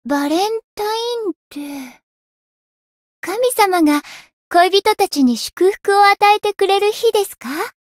灵魂潮汐-薇姬娜-情人节（相伴语音）.ogg